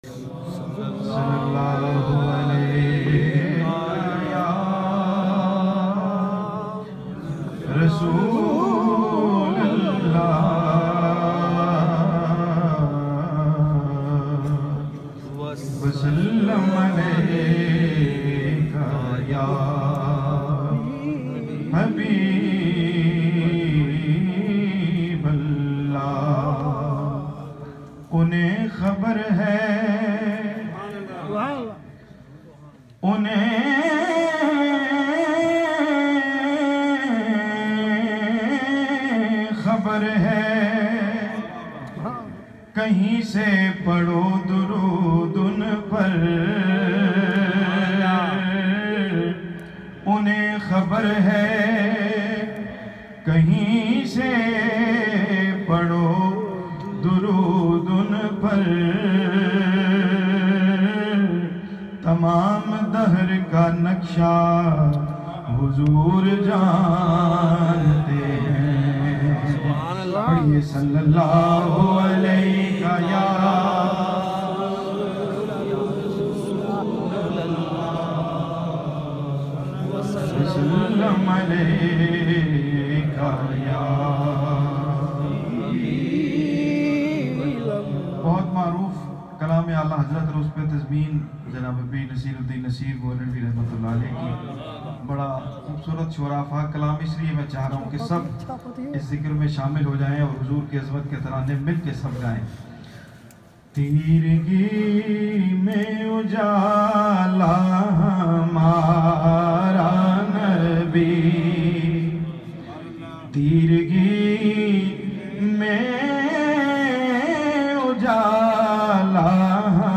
has very sweet and magical voice with wonderful control